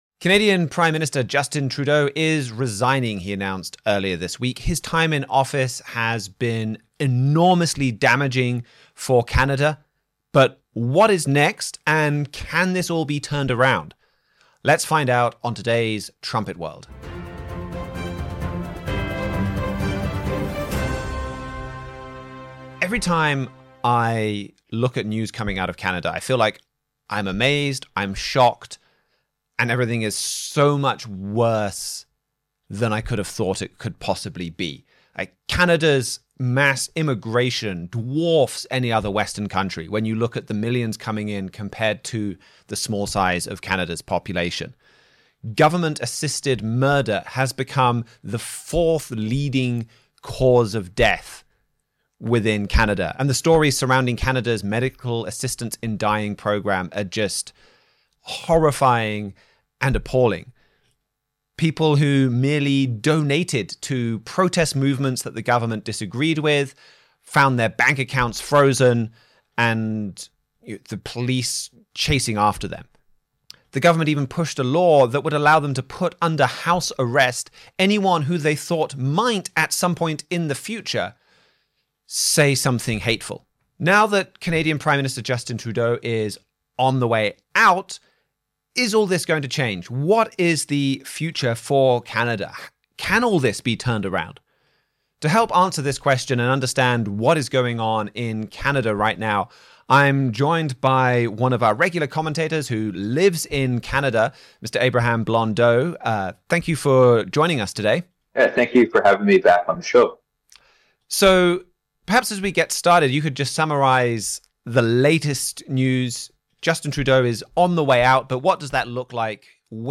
Join us for a lively discussion surrounding the miraculous shift in Canadian politics, who will replace Trudeau, the real causes behind the nation’s problems, and whether Canada will become a U.S. state.